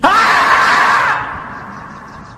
Squirrel Sound Effects MP3 Download Free - Quick Sounds